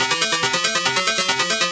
CS_FMArp C_140-C.wav